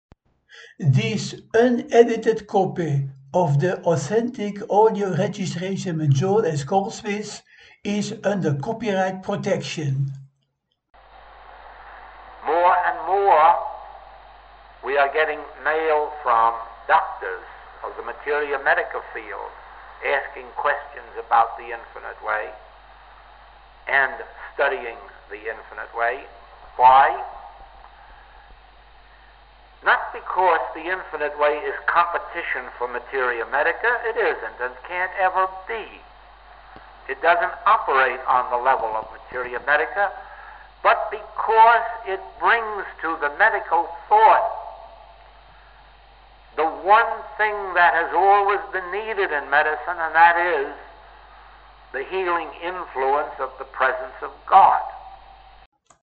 (Fragment from: 1962 Los Angeles Private Group, Tape 1 Track 2)